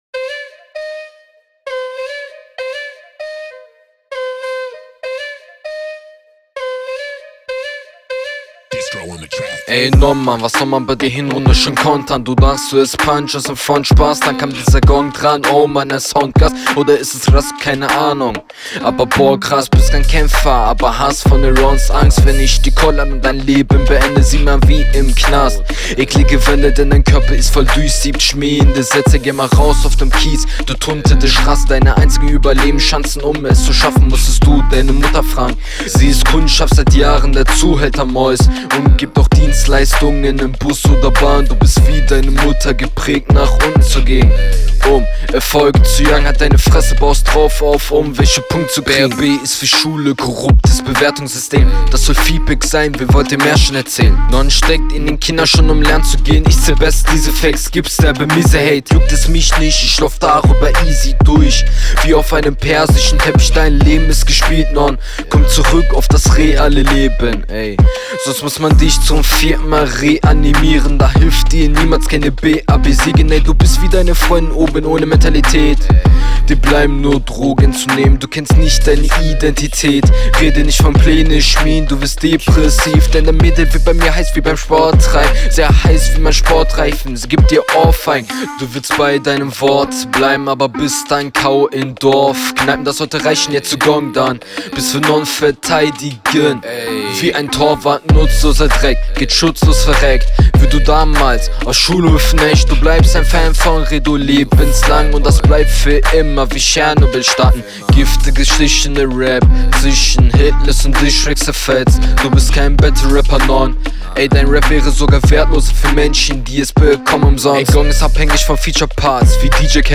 Runde zu lang aber hast dich vom Flow bisschen gesteigert